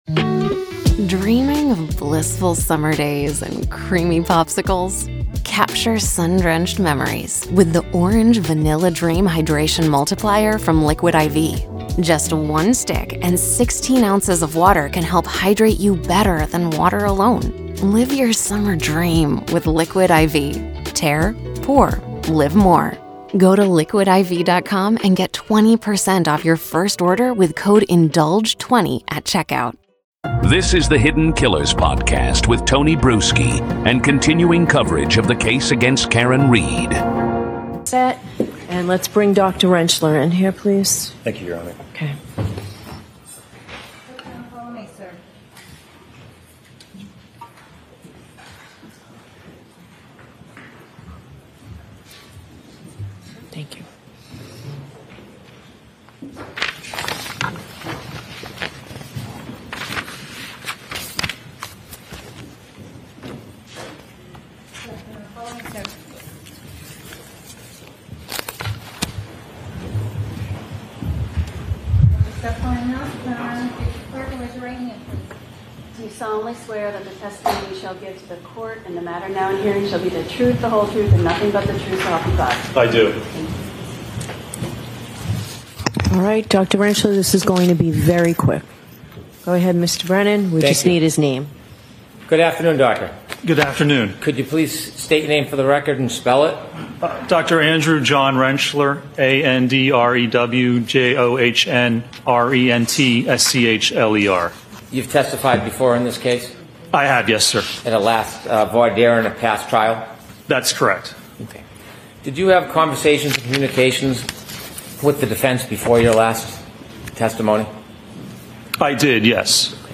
This episode captures the courtroom exchange and adds context to the growing scrutiny around the defense’s forensic claims.